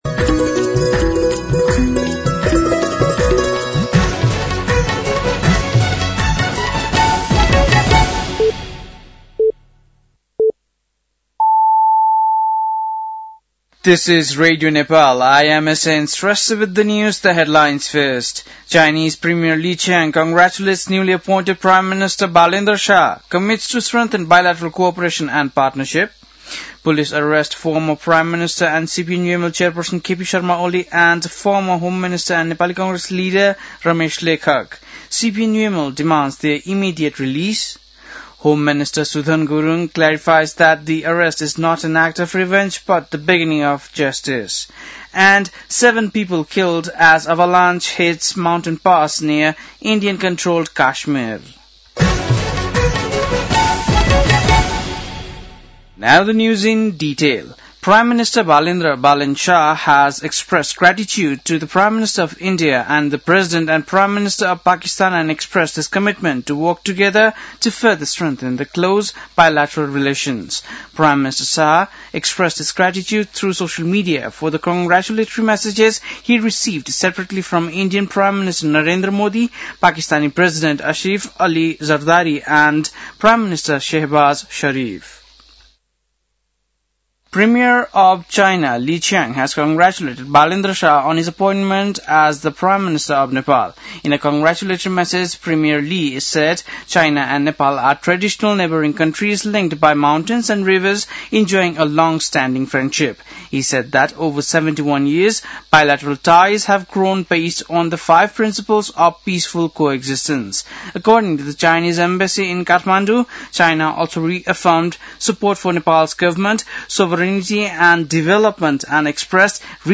बेलुकी ८ बजेको अङ्ग्रेजी समाचार : १४ चैत , २०८२
8-pm-news-1-1.mp3